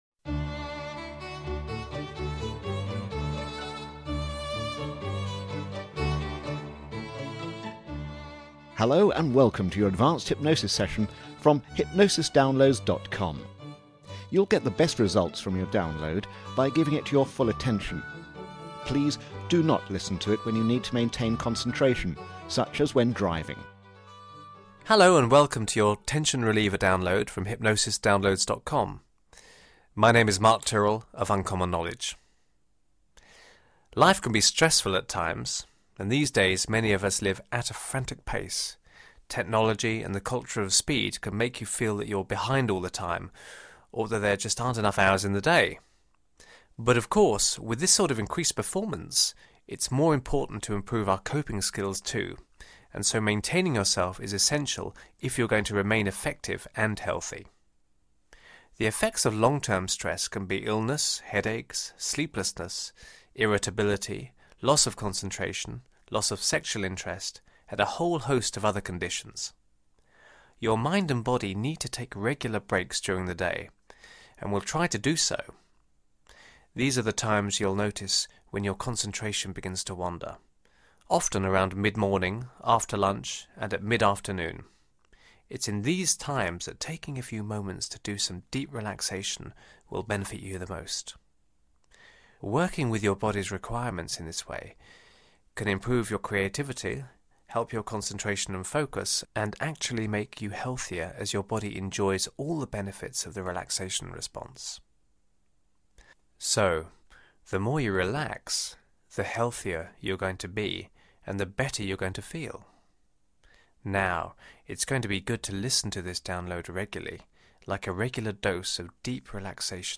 Meditate
Need a stress break? Get comfortable and listen to this relaxing meditation.